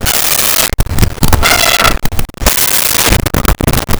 Cat Lonely Meows
Cat Lonely Meows.wav